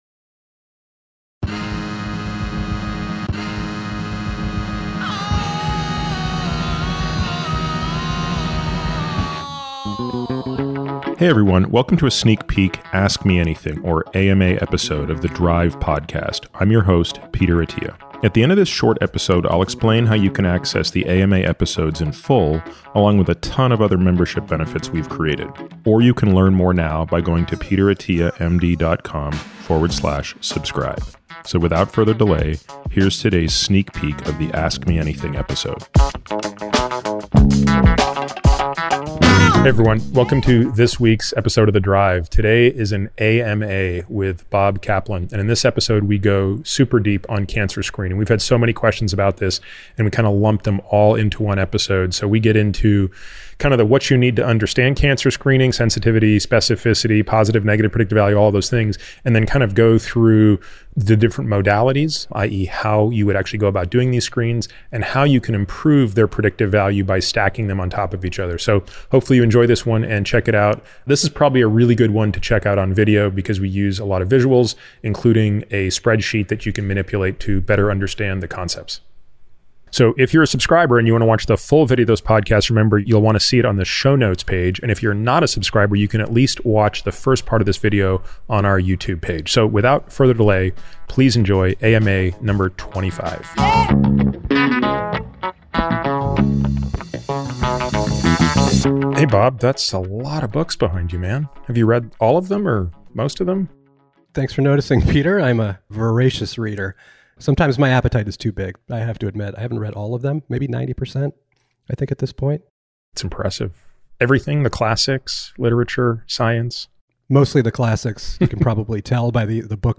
If you’re not a subscriber and listening on a podcast player, you’ll only be able to hear a preview of the AMA.